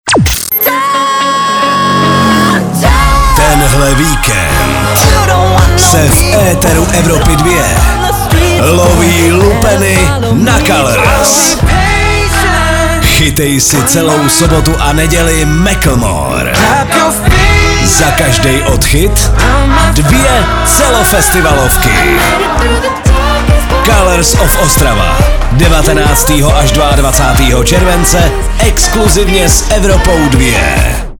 liner_colours_of_ostrava_vikend.mp3